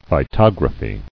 [phy·tog·ra·phy]